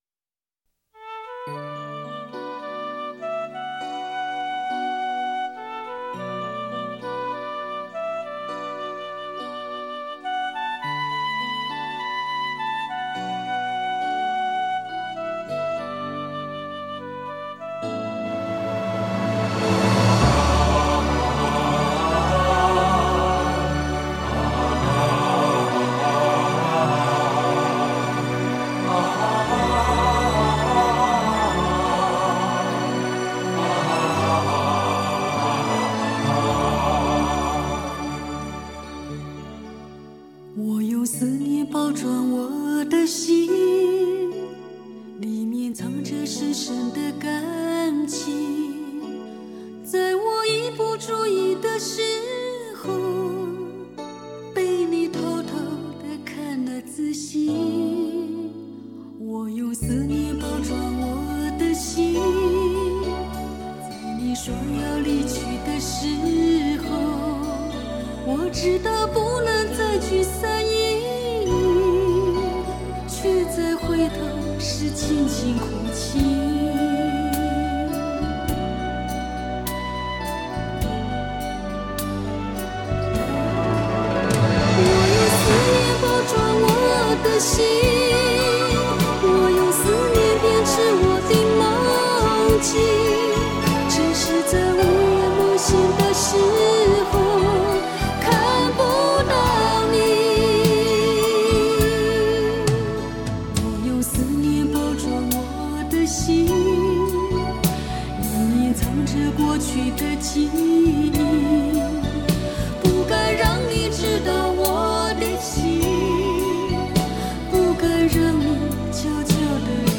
她的歌，用温柔浅唱 她的歌，在永恒转航